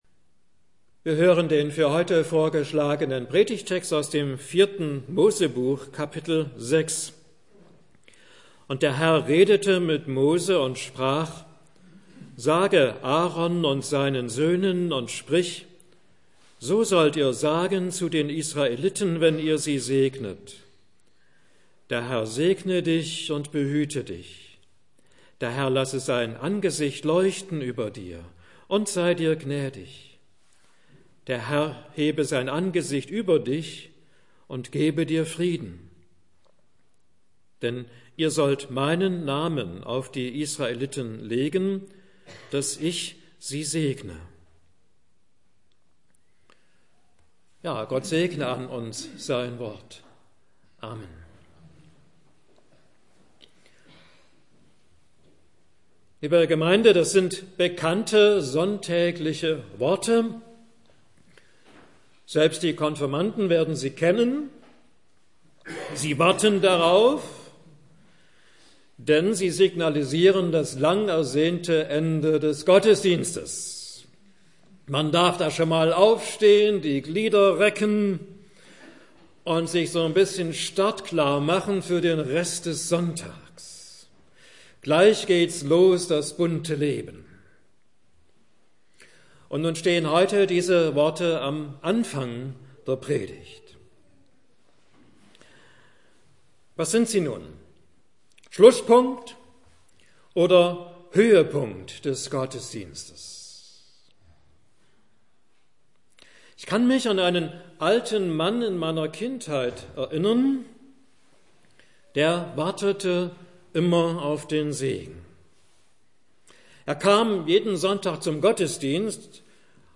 Mai 2015 Heruntergeladen 1328 Mal Kategorie Audiodateien Predigten Schlagwörter Segen , segnen , Numeri 6 , 4. Mose 6 Beschreibung: Unter Gottes Segen